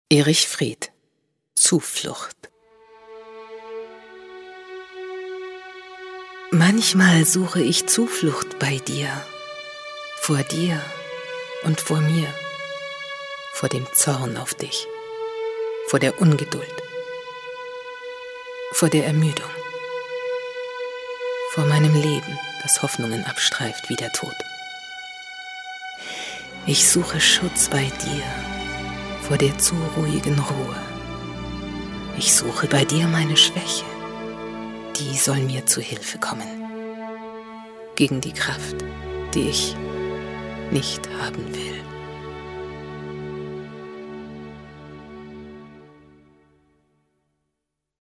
deutsche Profi-Sprecherin, einfühlsame, warme, sanfte, meditative, erotische oder auch zickige oder laute Stimme
Sprechprobe: Sonstiges (Muttersprache):
german female voice over artist